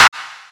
Clap 7.wav